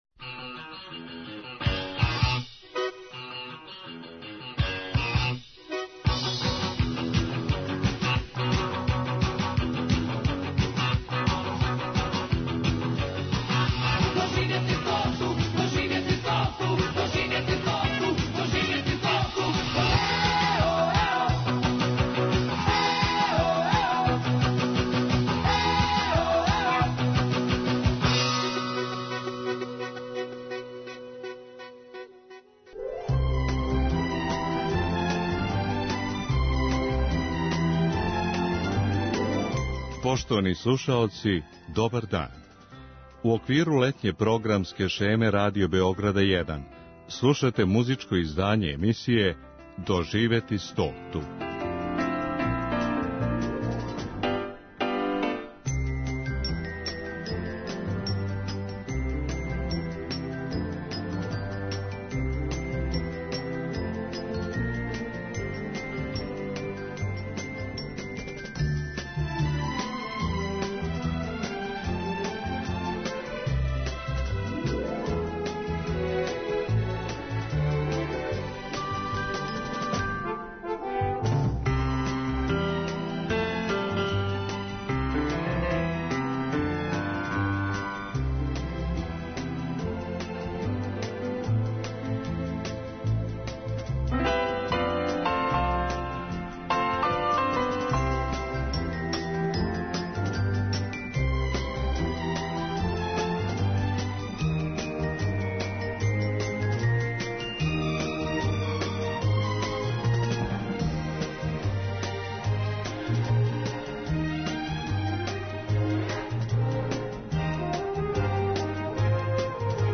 евергрин песмама